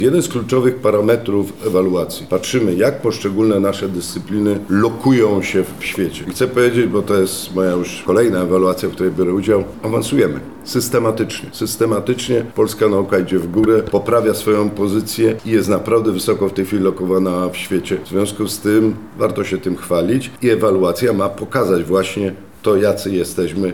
Zbigniew Kąkol– mówi prof. Zbigniew Kąkol, przewodniczący Komisji Ewaluacji Nauki z Akademii Górniczo-Hutniczej w Krakowie.